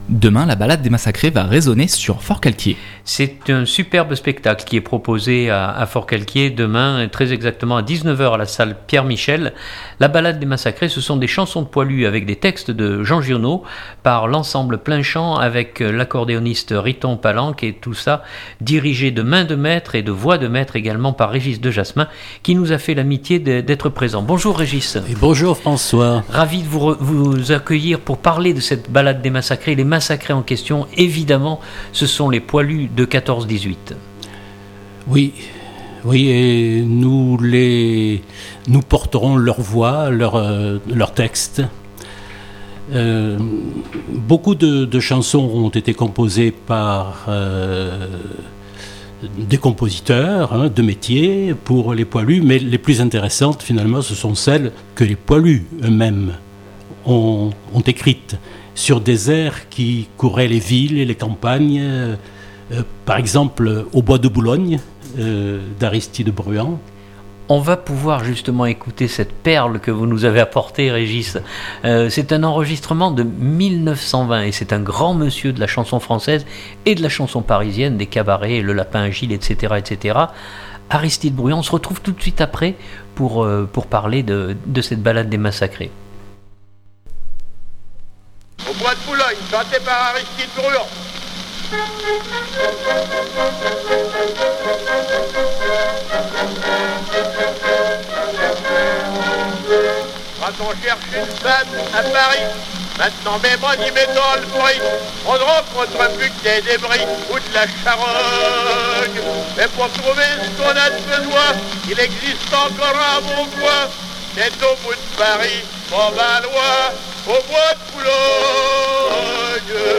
Cet artiste interprète des airs de la Grande Guerre.